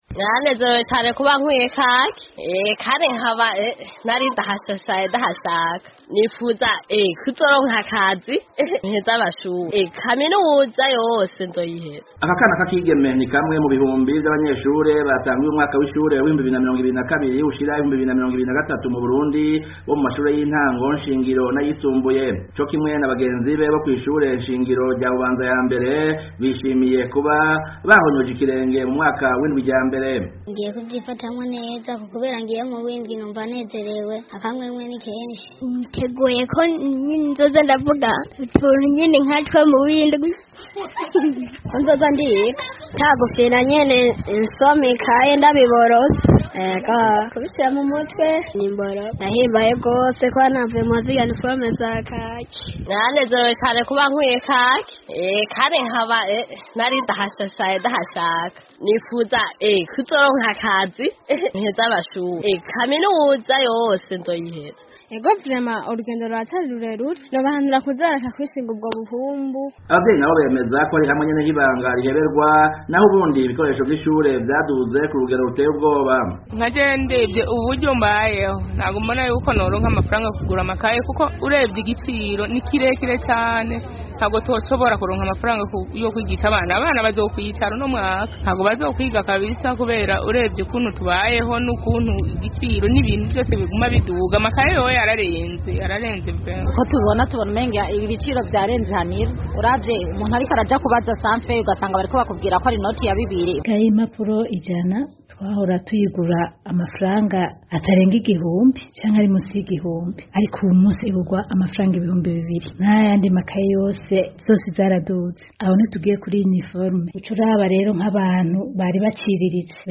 Fyonda munsi wumve amajwi y'abatandukanye mu nkuru